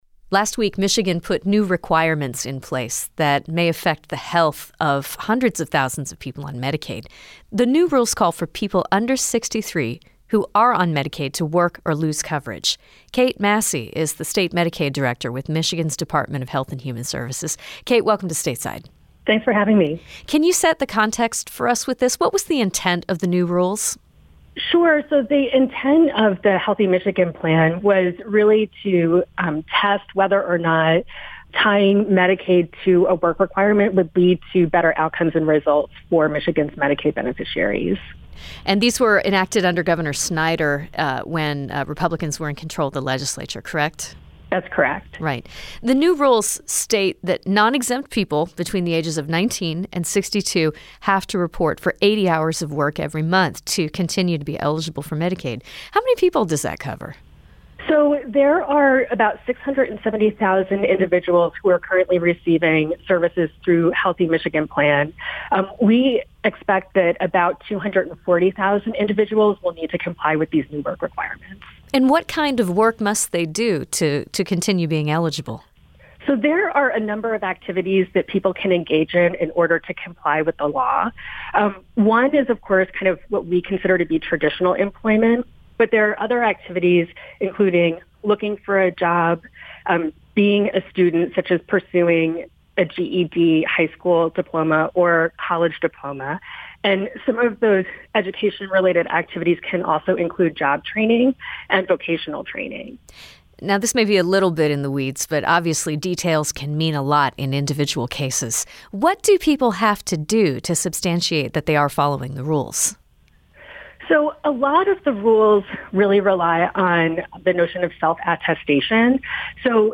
Sen. Gary Peters joined Stateside to discuss whether the killing was justified and what could happen next.